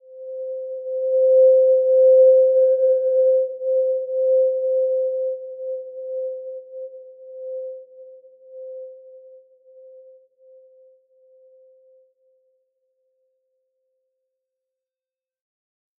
Simple-Glow-C5-p.wav